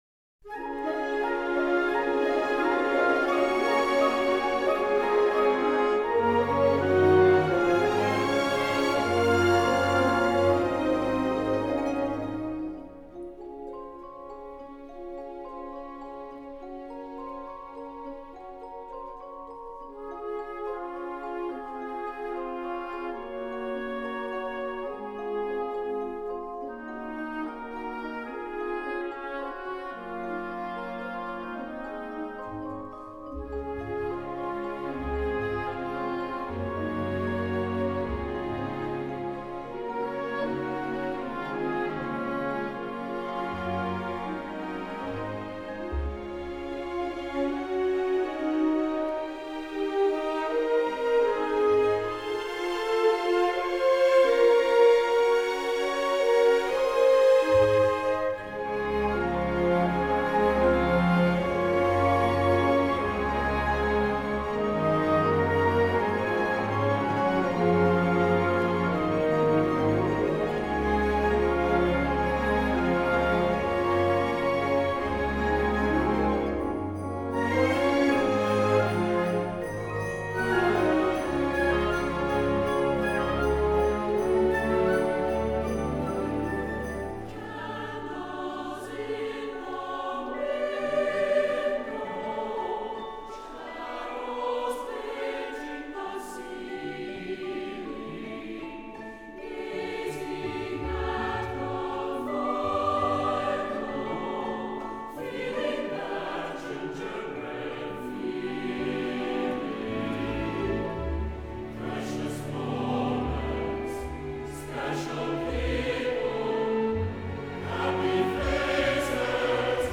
Второй трек — вот тут оркестр, киношный такой.